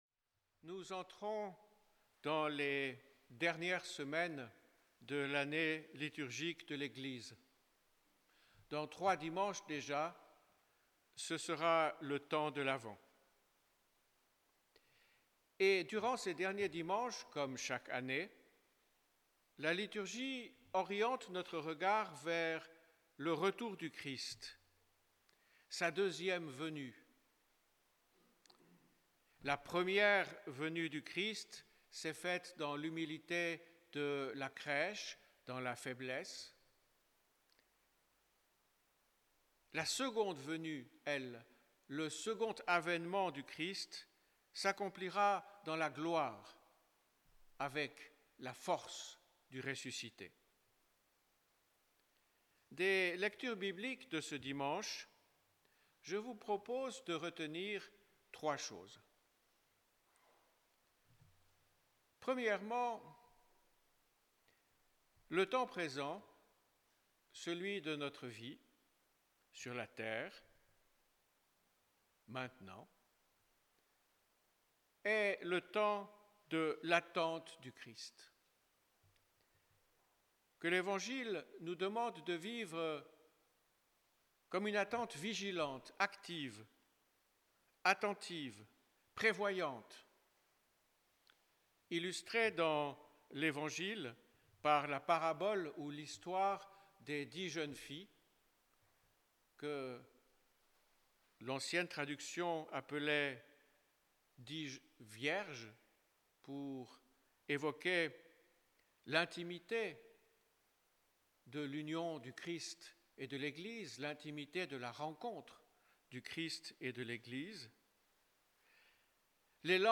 Enregistrement en direct